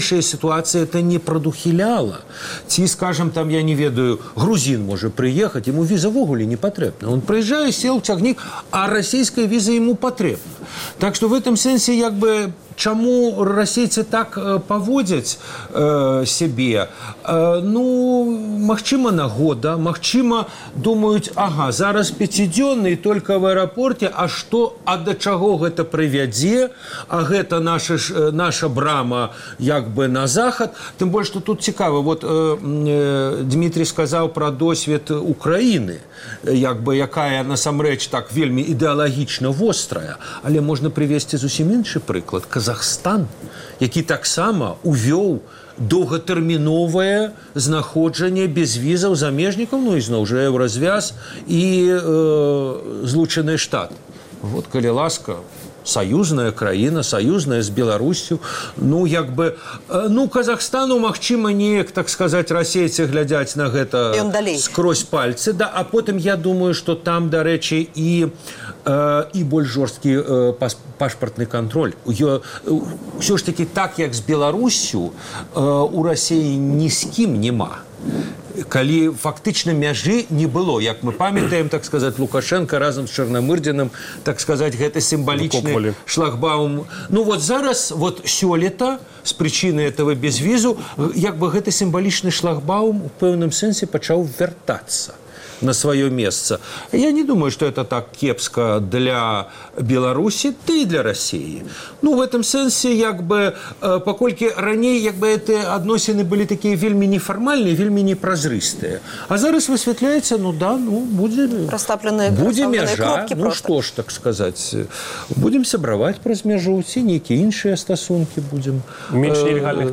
А зараз – скарочаная радыёвэрсія перадачы.